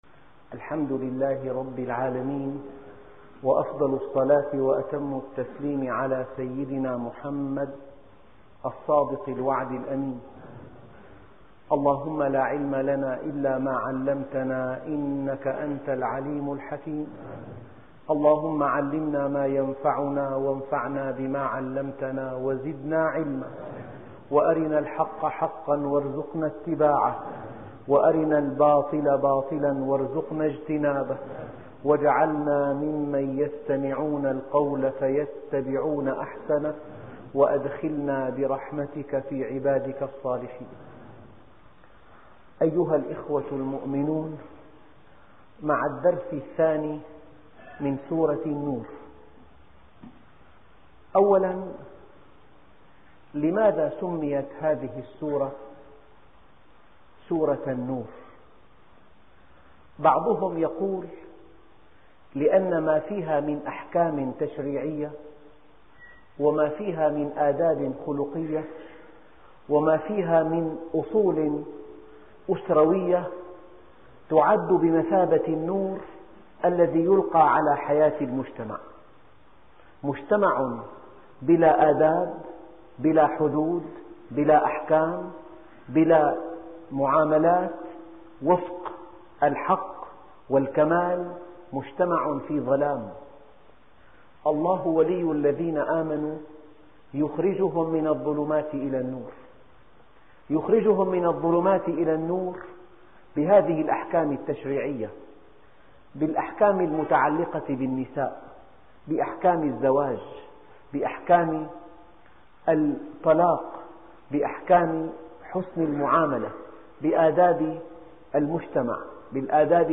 الدرس ( 2) نور على نور- تفسير سورة النور - الشيخ محمد راتب النابلسي